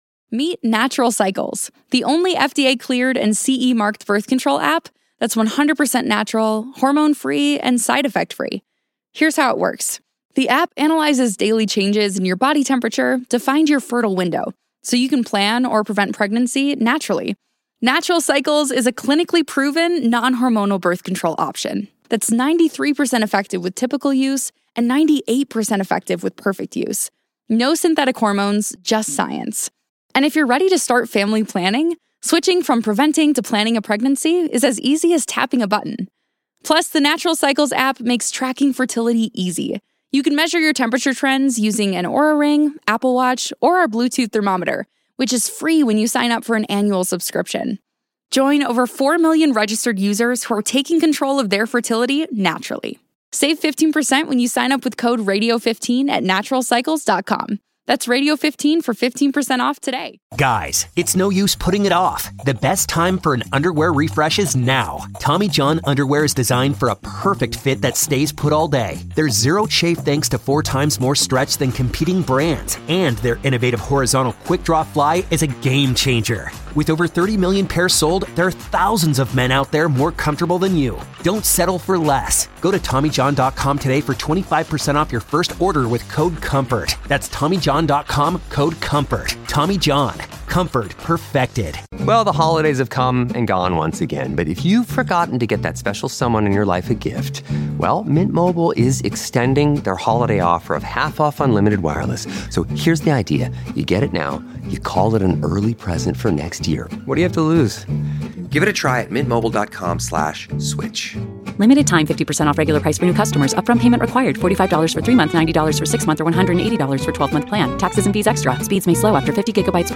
chatting on the phone